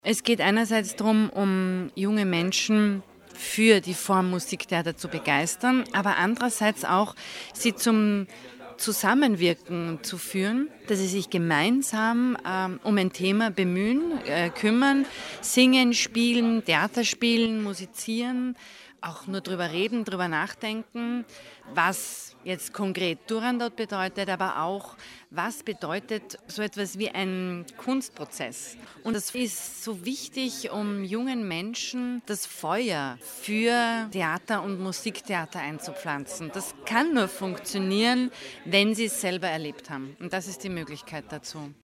O-Töne crossculture-Pressekonferenz - News